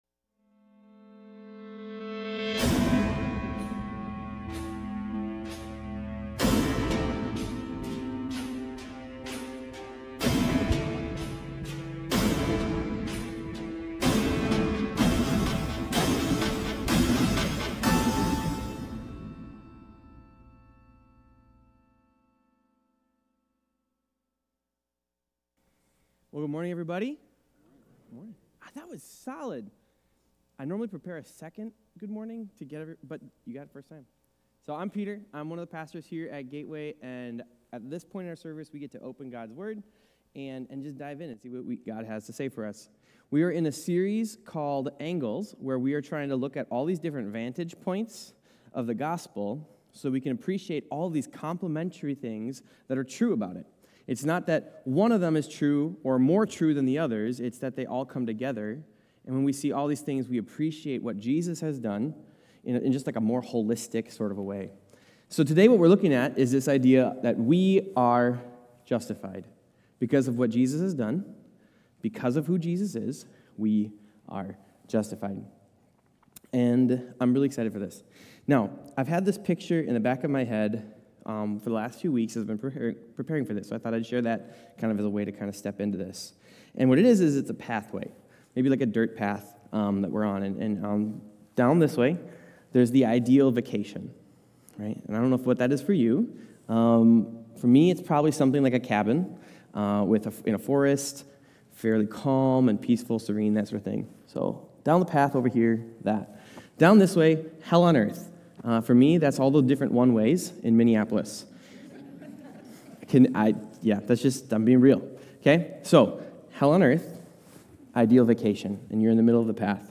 We-Are-Justified-Sermon-3.2.25.m4a